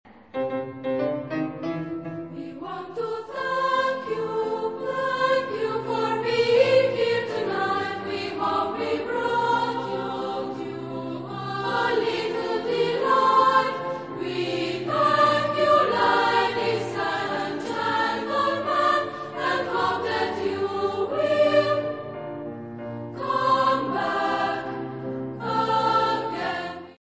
Type de choeur :  (2 voix )